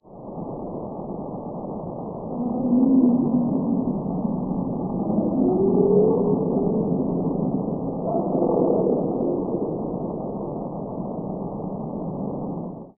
Sound of Bowhead whale